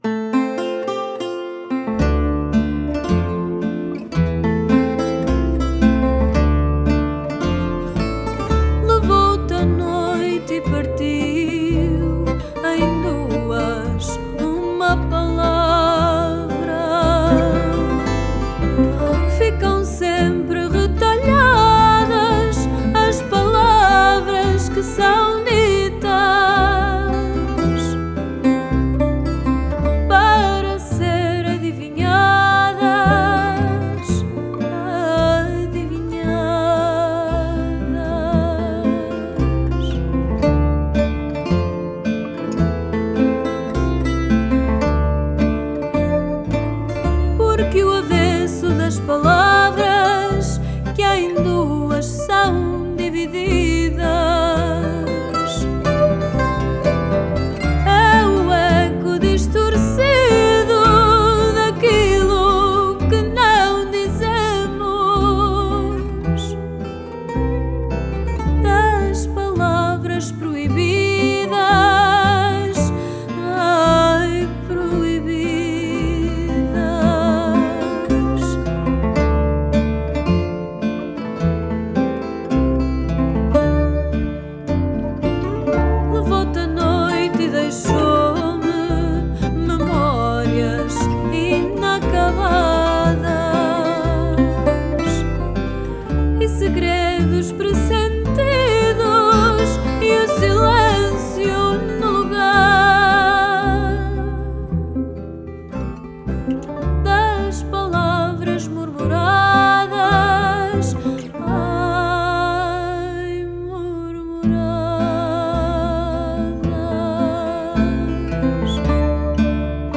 Style: Fado